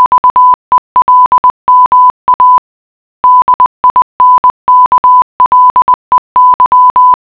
Here is some morse code for you to decipher!
morse.wav